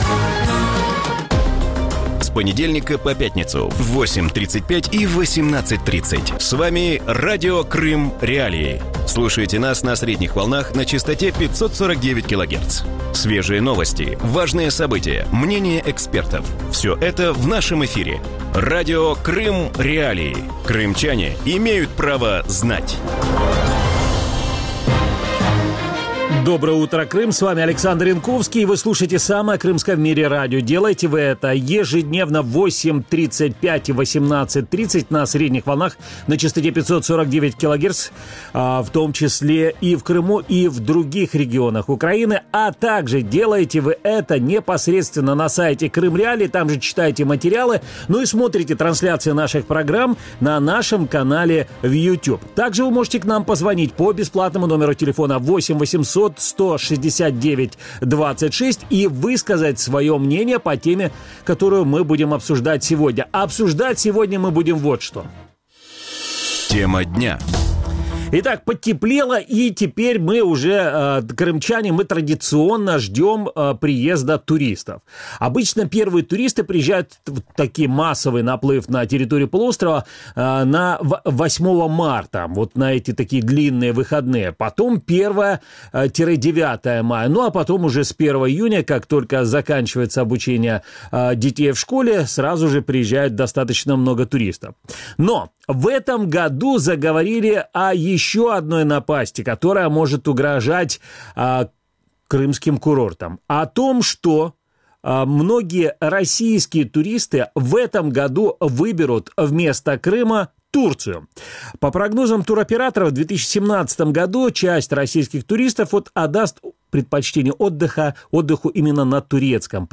Утром в эфире Радио Крым.Реалии говорят о подготовке к туристическому сезону.